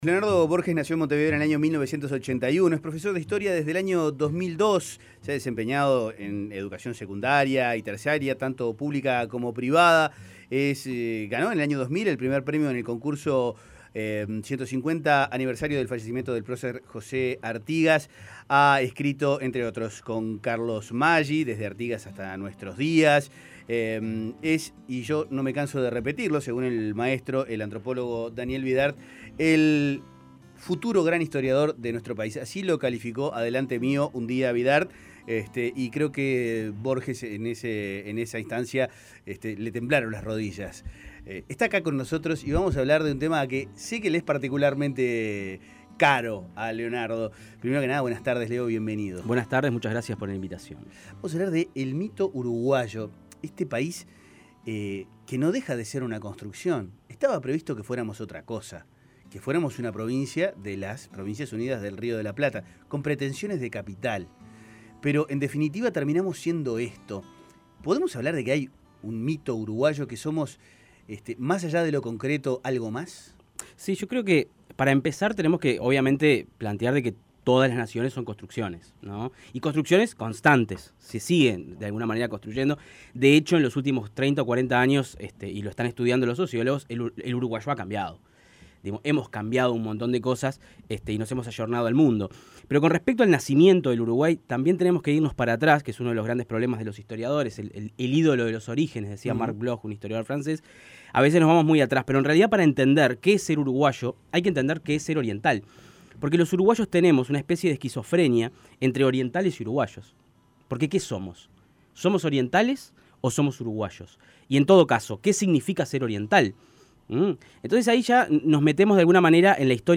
Escuche la entrevista Con Tiempo